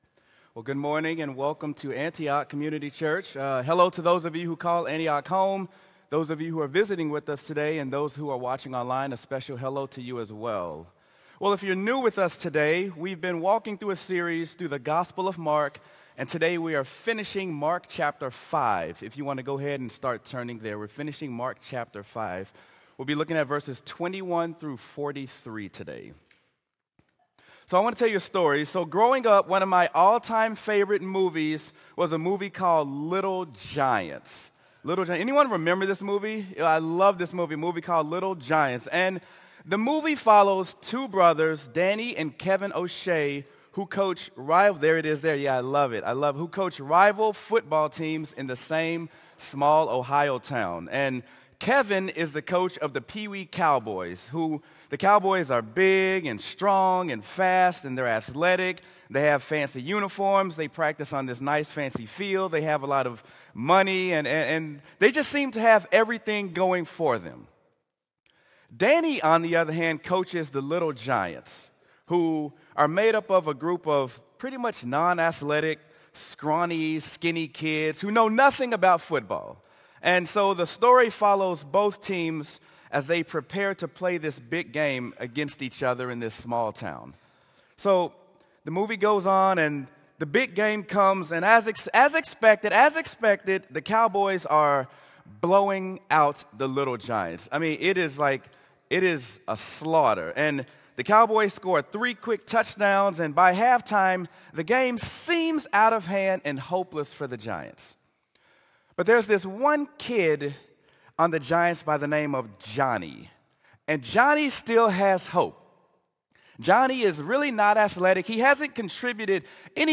Sermon: Mark: When All Hope Seems Lost
sermon-mark-when-all-hope-seems-lost.m4a